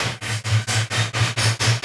Index of /musicradar/rhythmic-inspiration-samples/130bpm